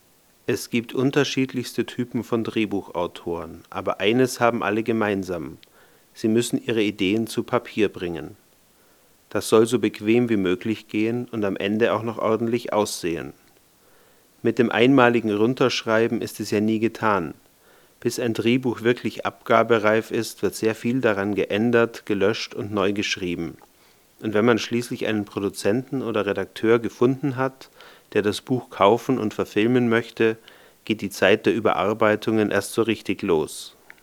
Sprachaufnahmen - Bitte um Beurteilung
Alle wurden hier in meinem Wohnzimmer (Dachwohnung mit Teppichboden) aus ca. 20 cm Abstand gemacht und anschließend in Audacity normalisiert; weitere Bearbeitung steckt noch nicht drin.